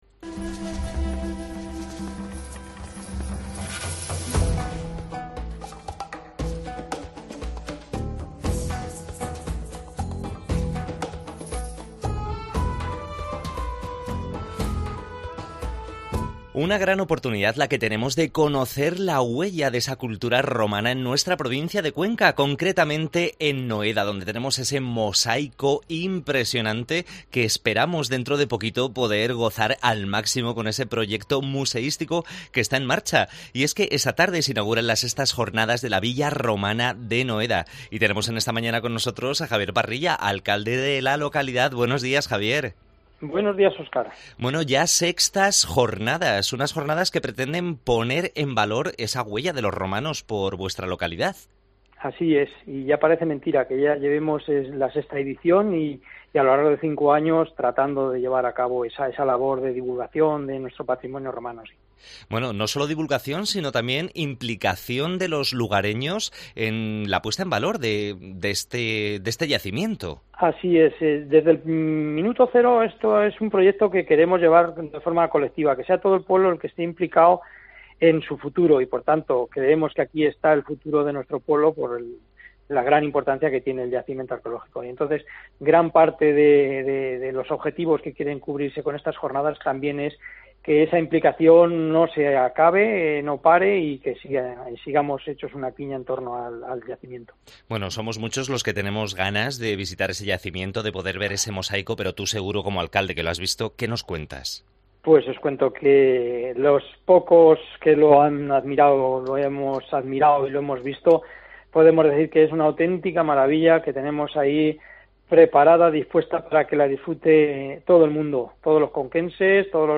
Entrevista a javier Parrilla, alcalde de Villar de Domingo Garcia, organizador de las Jornadas